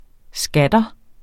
Udtale [ ˈsgadʌ ]